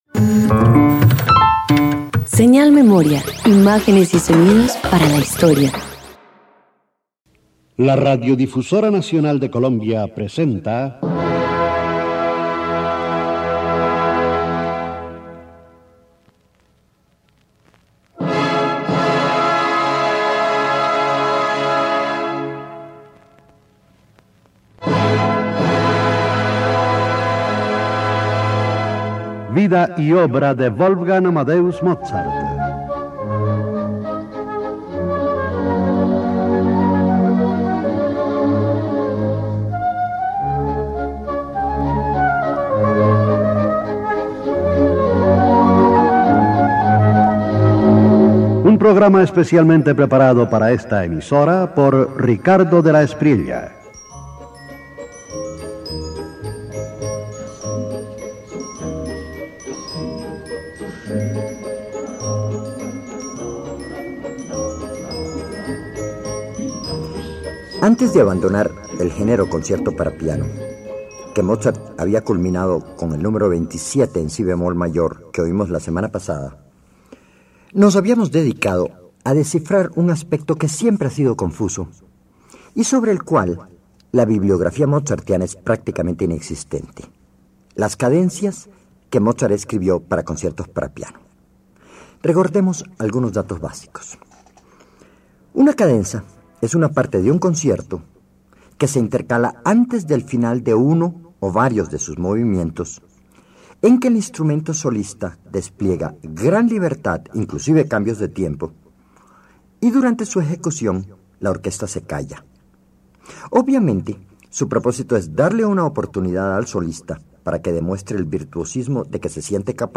320 Cadenzas para piano y orquesta Parte I_1.mp3